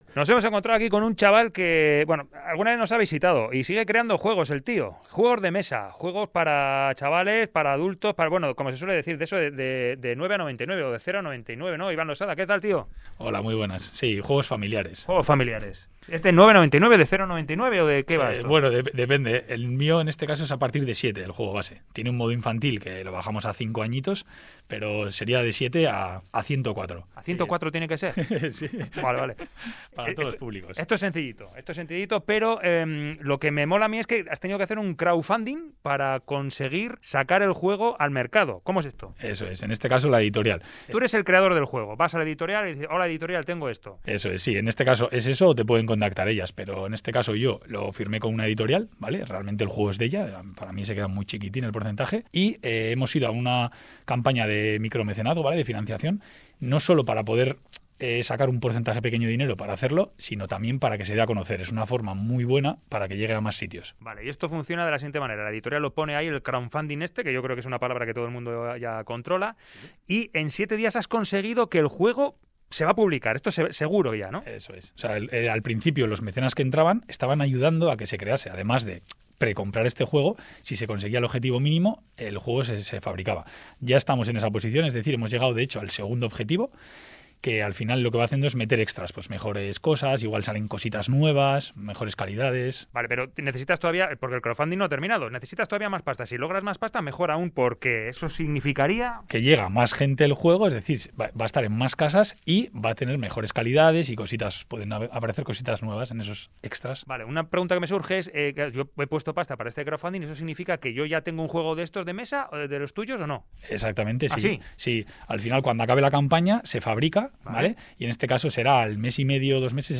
Audio: Reportaje: ¿Hacemos un juego de mesa?